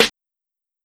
Snare (Reminder).wav